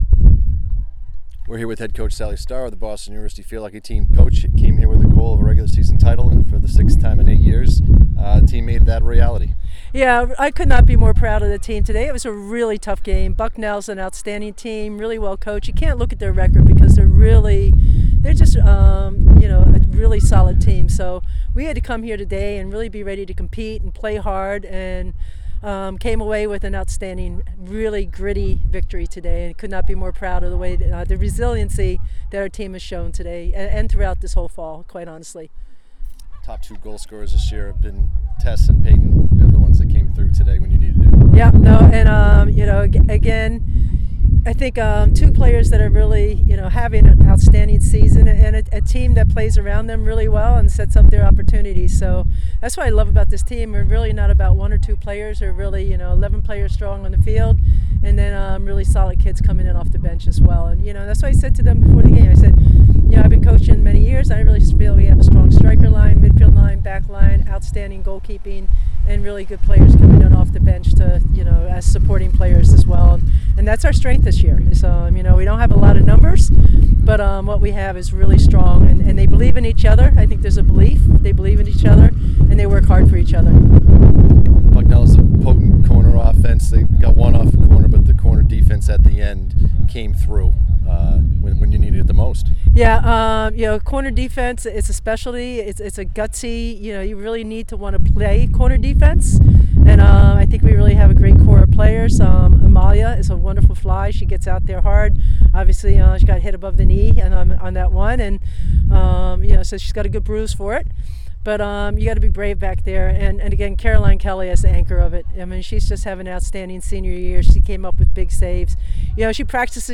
Field Hockey / Bucknell Postgame (10-30-22) - Boston University Athletics